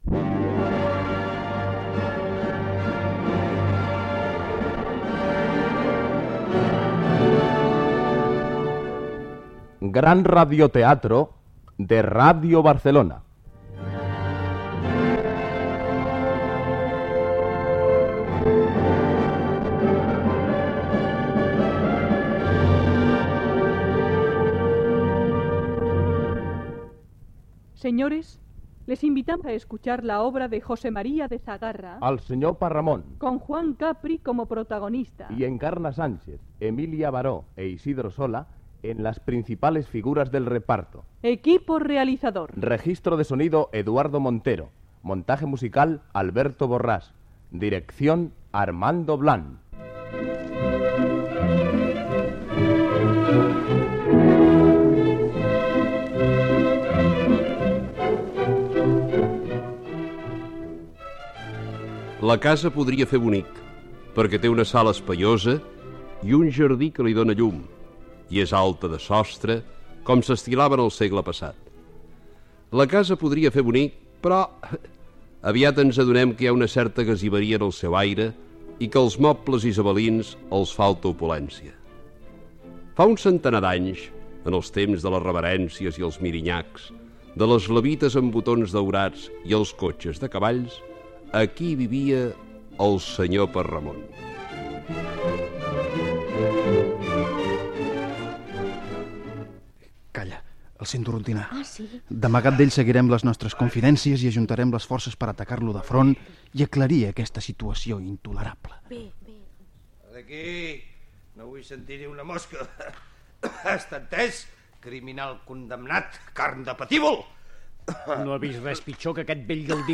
Adaptació de l'obra "El Senyor Perramon" de Josep Maria de Segarra. Careta del programa amb l'equip.
Ficció
L'Archimag recull la digitalització del fons de cintes magnetofòniques en bobines obertes que conté un recull de la programació històrica de Ràdio Barcelona.